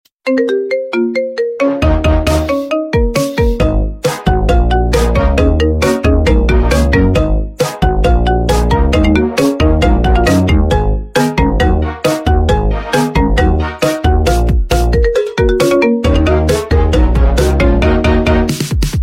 Sped Up & Slowed Versions